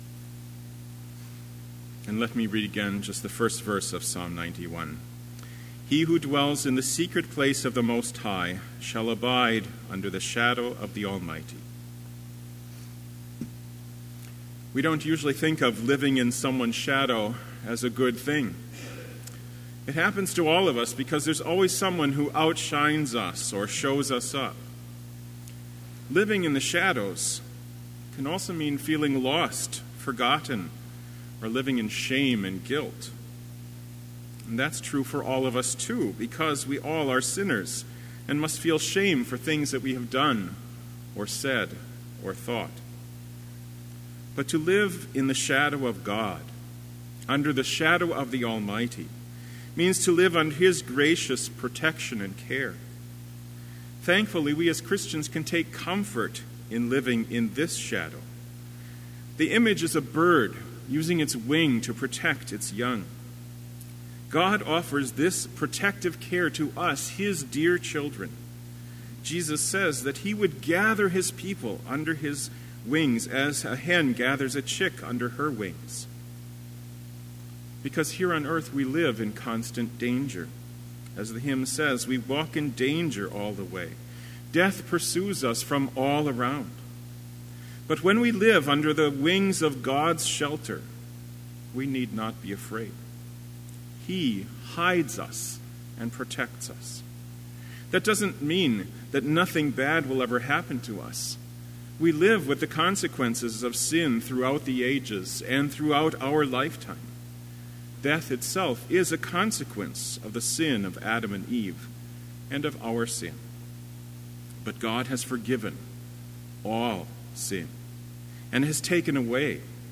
Complete Service
This Chapel Service was held in Trinity Chapel at Bethany Lutheran College on Wednesday, February 21, 2018, at 10 a.m. Page and hymn numbers are from the Evangelical Lutheran Hymnary.